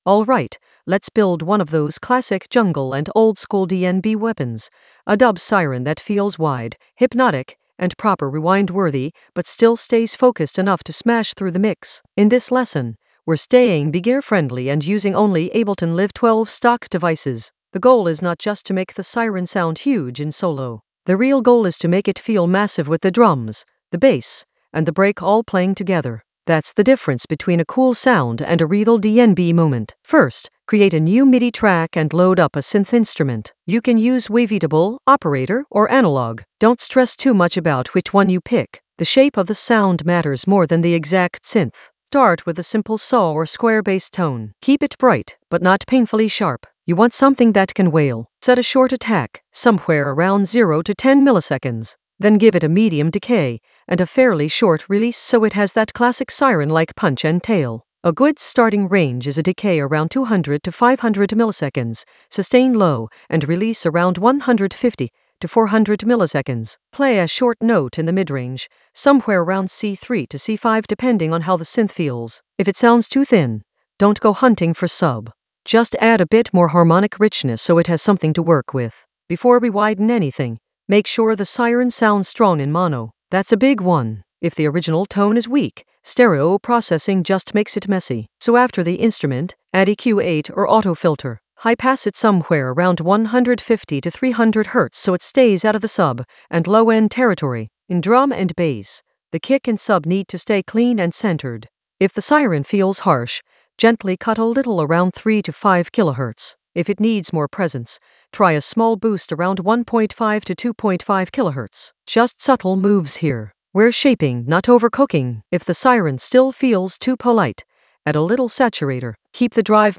An AI-generated beginner Ableton lesson focused on Widen a dub siren for rewind-worthy drops in Ableton Live 12 for jungle oldskool DnB vibes in the Drums area of drum and bass production.
Narrated lesson audio
The voice track includes the tutorial plus extra teacher commentary.